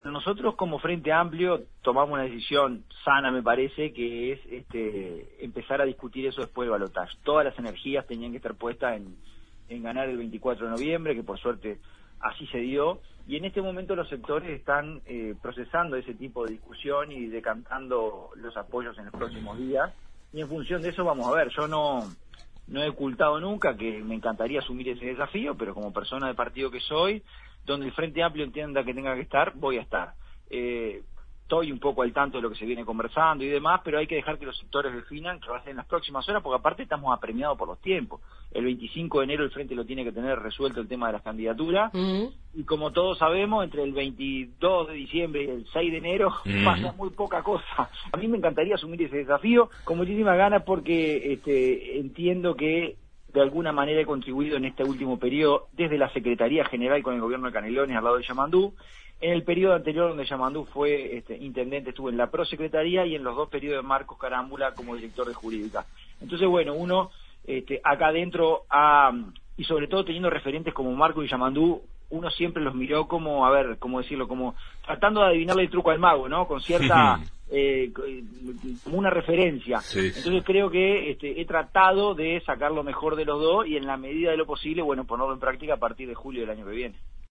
En Justos y pecadores entrevistamos a Francisco Legnani, secretario general de la Intendencia de Canelones.